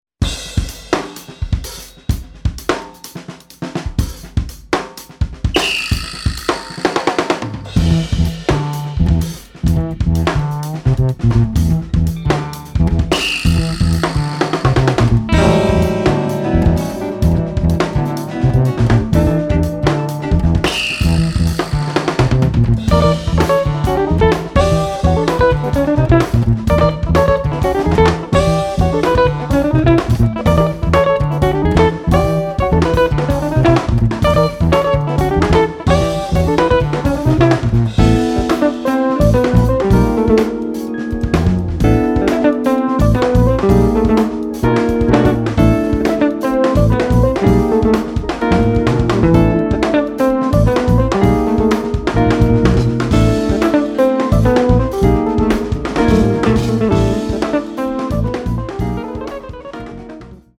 guitars
bass
drums
keyboards
tabla
Mongolian throat singing
percussion
Hindustani vocal